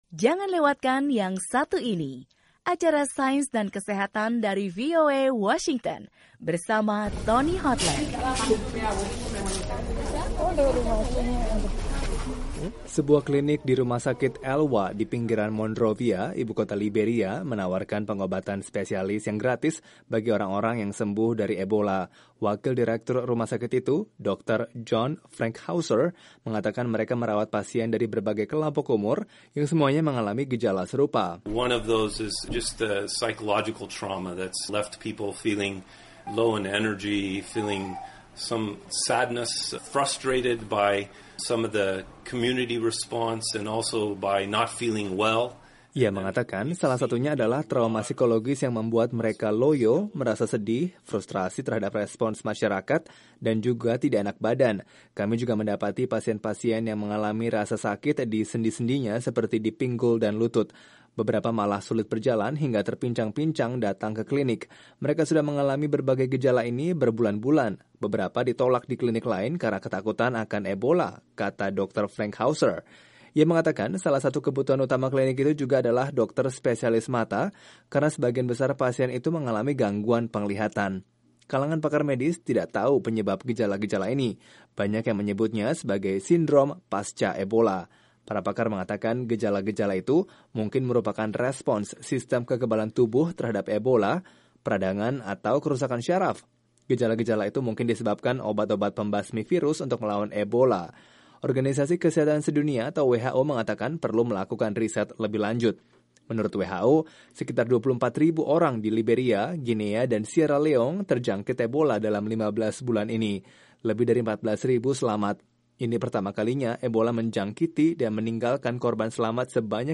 Sejumlah pasien yang berhasil sembuh dari virus Ebola kini mengalami masalah kesehatan baru seperti masalah penglihatan dan sakit kronis. Laporan VOA